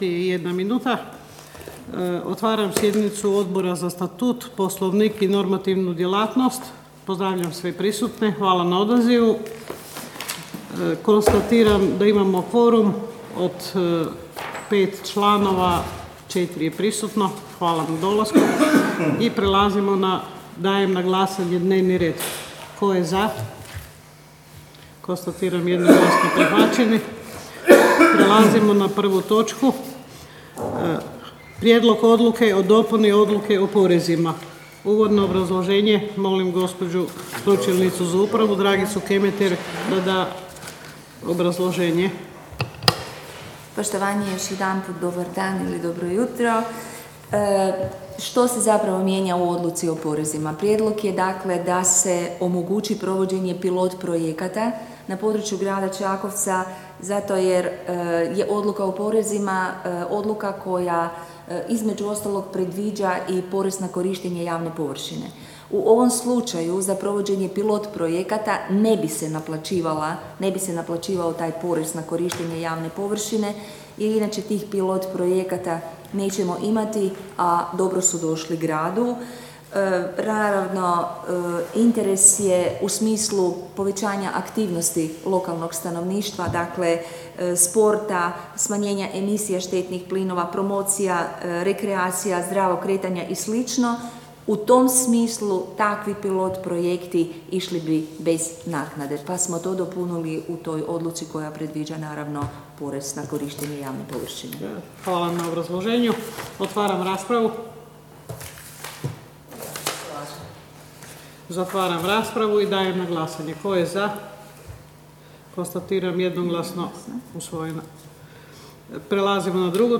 Obavještavam Vas da će se 5. sjednica Odbora za Statut, Poslovnik i normativnu djelatnost Gradskog vijeća Grada Čakovca održati dana 26. travnja 2022. (utorak), u 9.00 sati, u vijećnici Uprave Grada Čakovca.